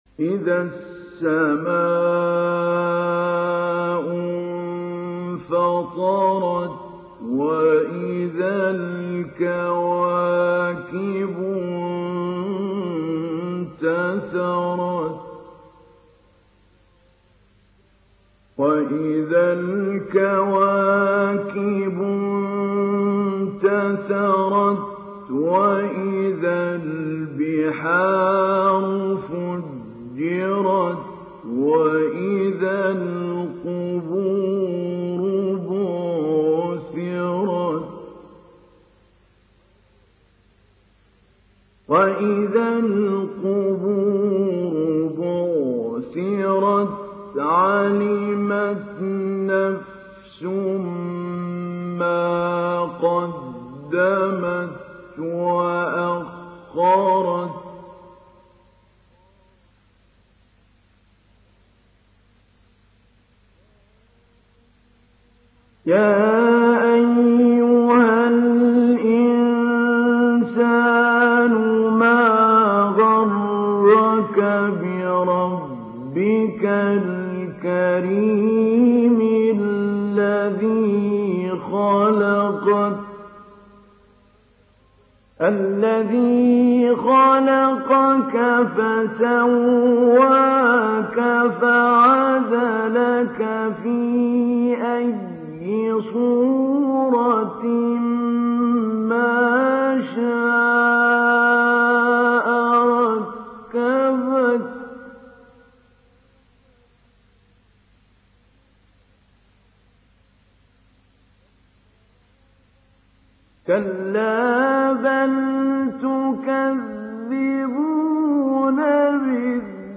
تحميل سورة الانفطار mp3 بصوت محمود علي البنا مجود برواية حفص عن عاصم, تحميل استماع القرآن الكريم على الجوال mp3 كاملا بروابط مباشرة وسريعة
تحميل سورة الانفطار محمود علي البنا مجود